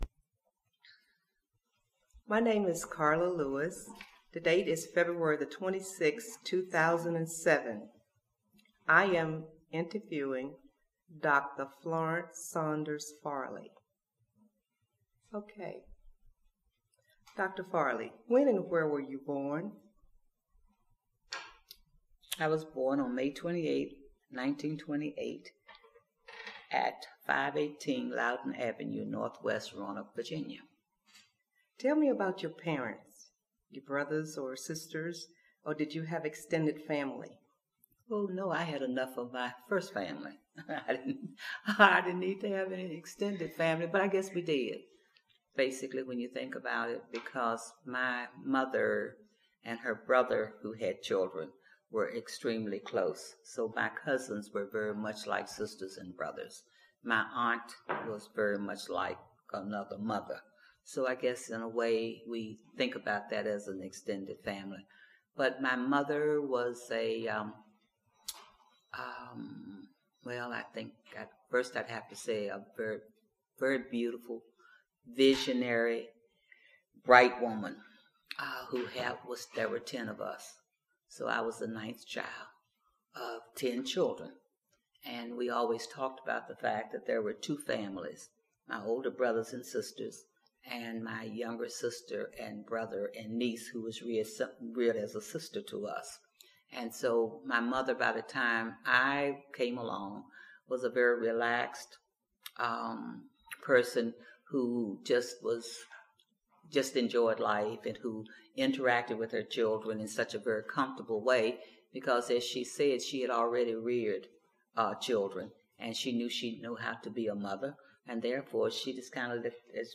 Location: Gainsboro Branch Library